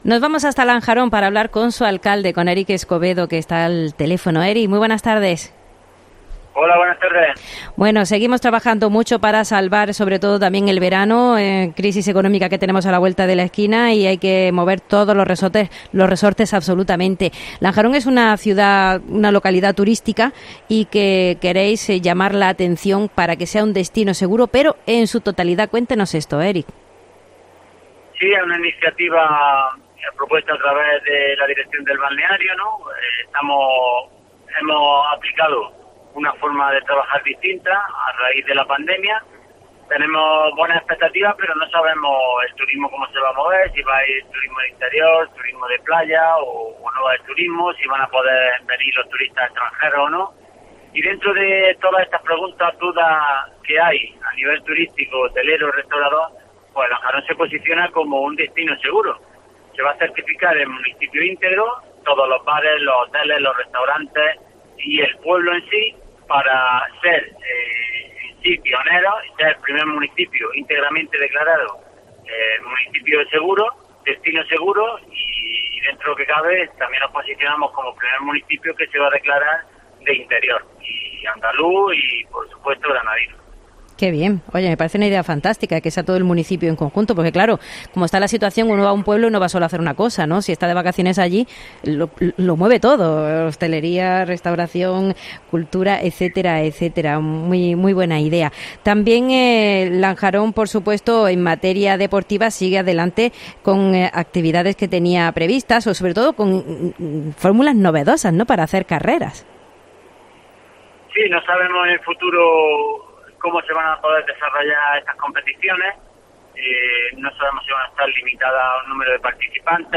El alcalde de Lanjarón, Eric Escobedo, ya nos adelantaba ayer a COPE que sería imposible celebrar las fiestas por las recomendaciones que llegaban desde las autoridades sanitarias.